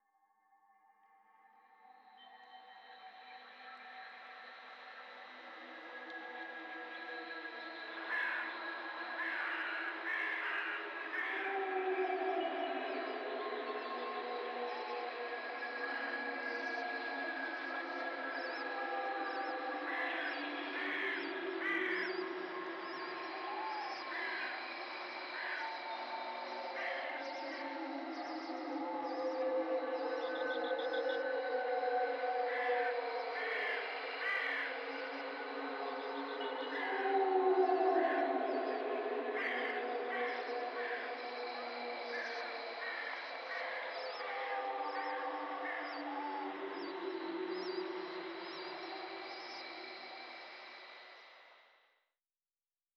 02_进门过道.wav